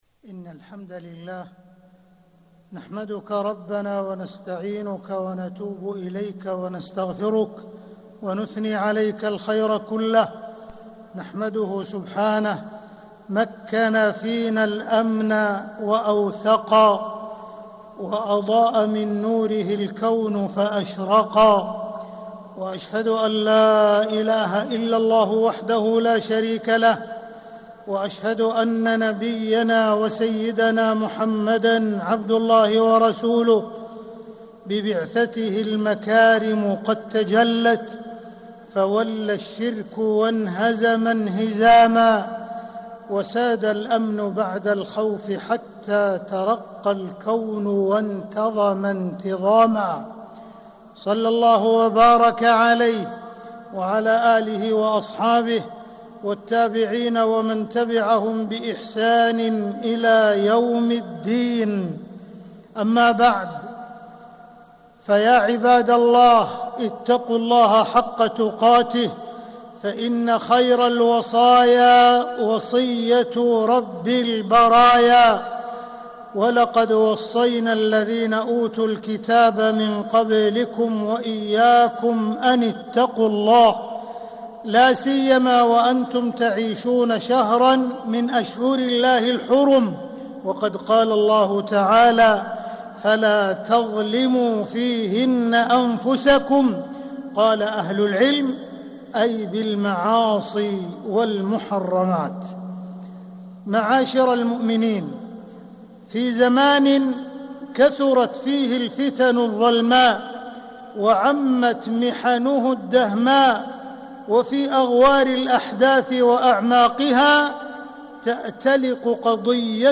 مكة: أهمية أمن الحج والحجاج - عبد الرحمن بن عبدالعزيز السديس (صوت - جودة عالية